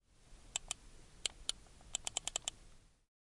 办公室 " 用鼠标点击
描述：点击Corsair M60。记录了AT2020
标签： 办公室 鼠标 电脑
声道立体声